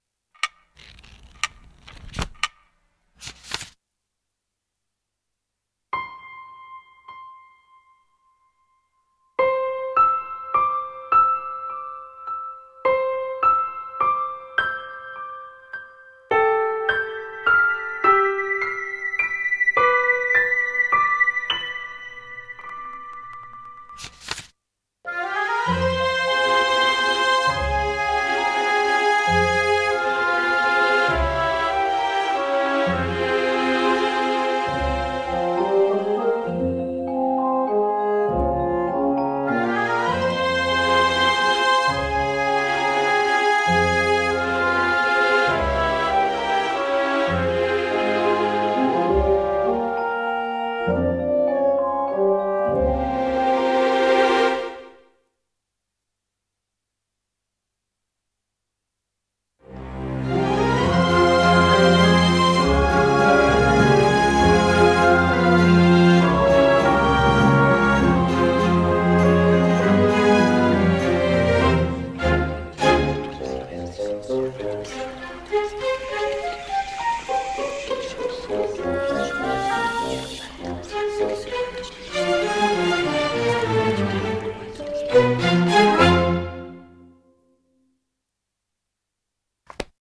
CM風声劇